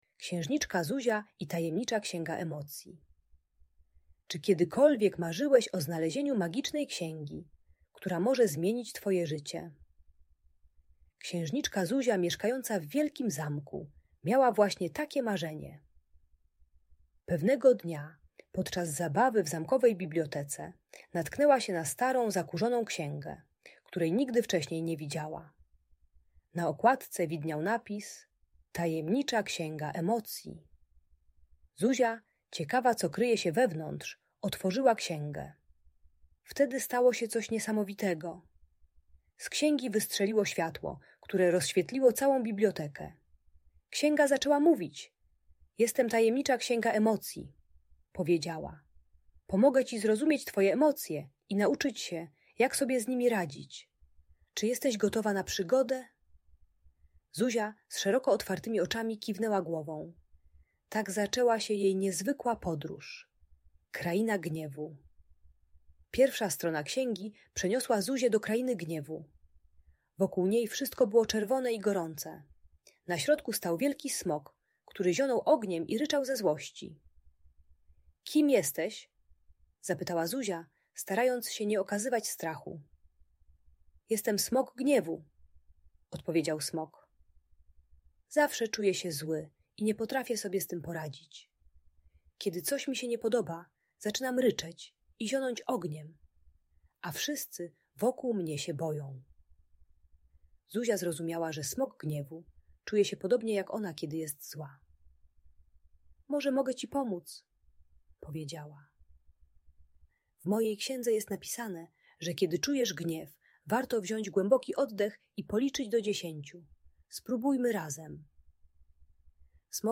Księżniczka Zuzia i Tajemnicza Księga Emocji - Bunt i wybuchy złości | Audiobajka